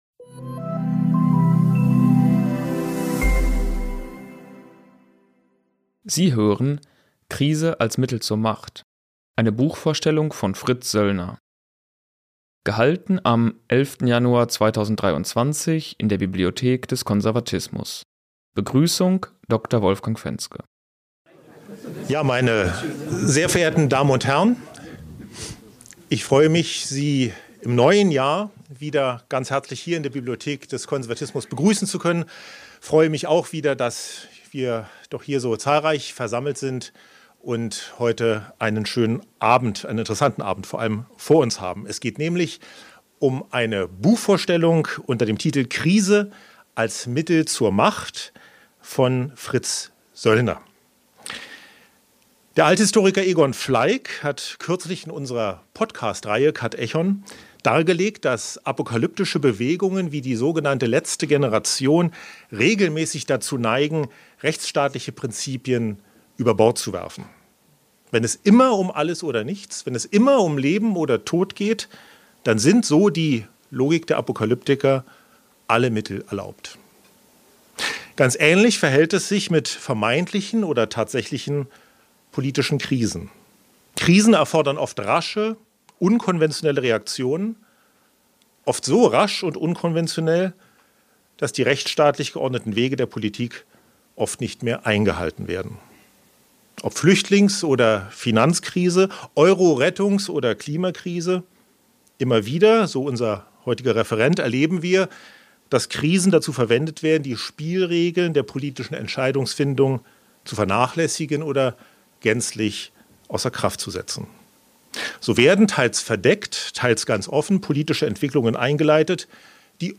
Der Ökonom warnte in seinem Vortrag vor den Folgen der verfehlten Geldpolitik der letzten Jahre.